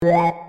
Sequencial Circuits - Prophet 600 29